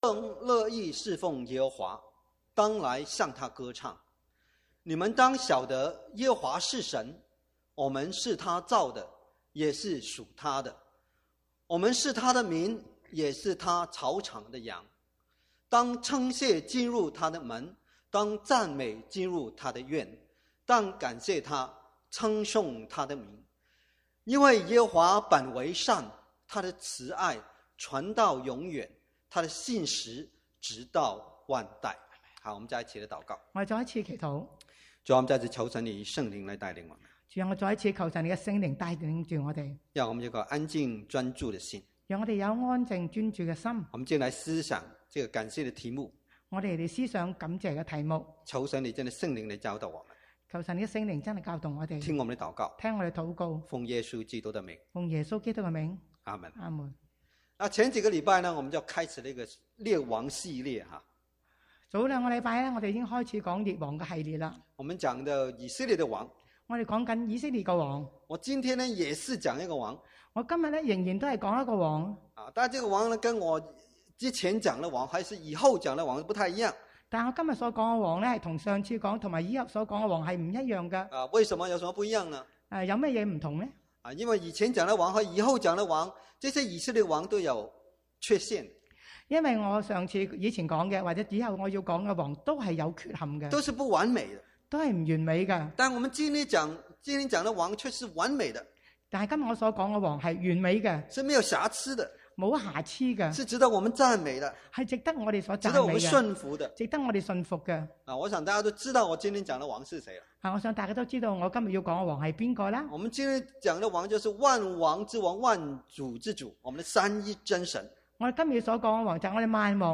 Combined Service
From Series: "Chinese Sermons"